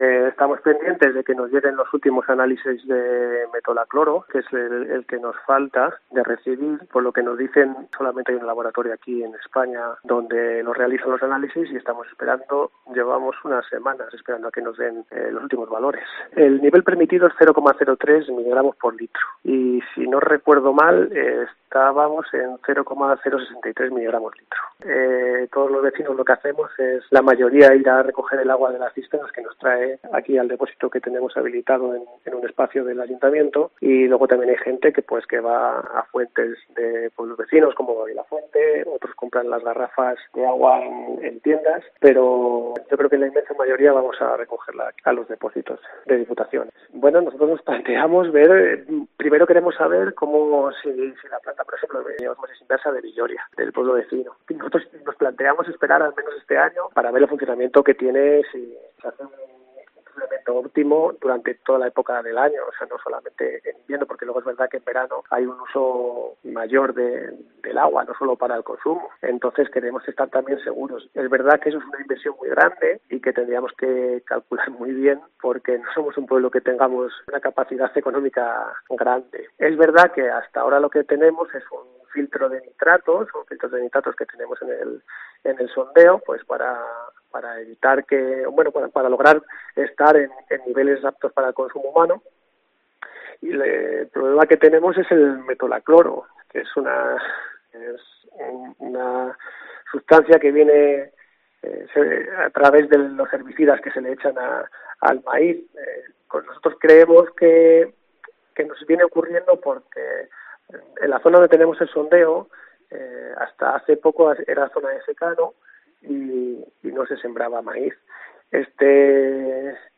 Javier Miguel de la Torre, alcalde de Villoruela explica a COPE que hay metalocloro en el agua